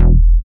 70.03 BASS.wav